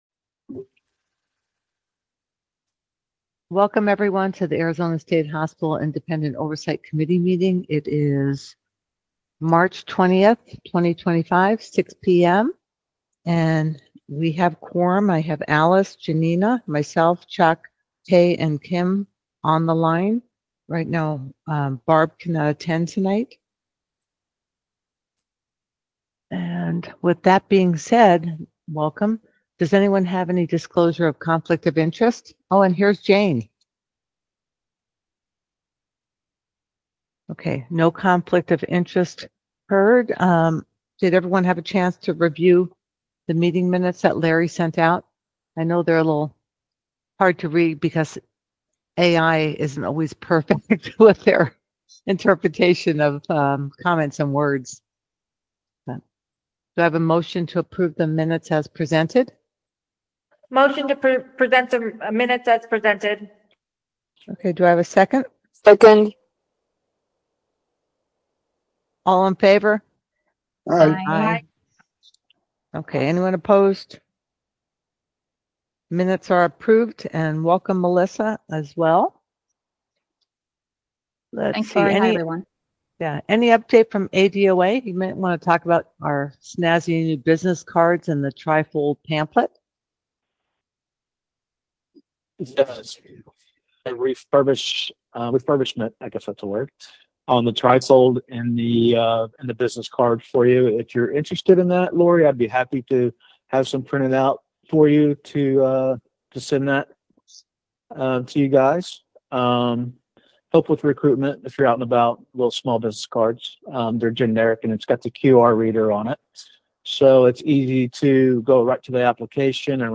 Virtual Meeting Only